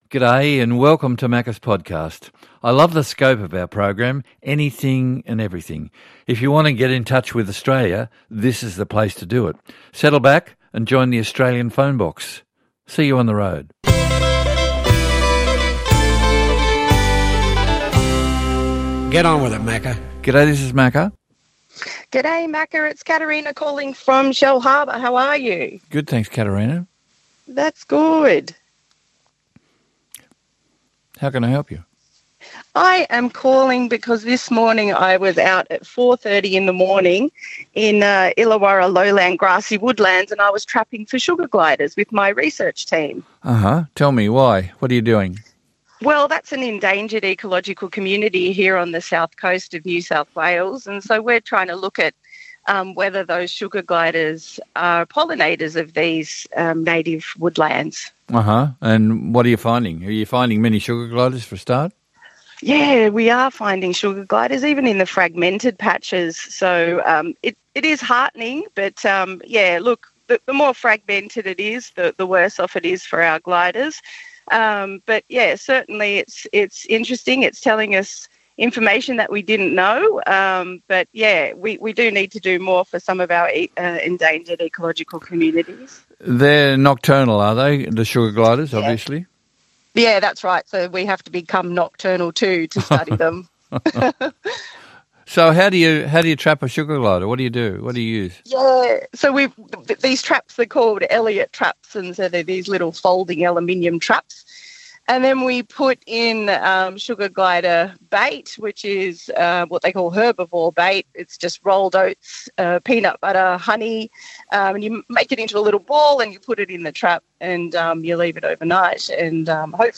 Some of the best calls and stories from this week's Australia All Over with Macca.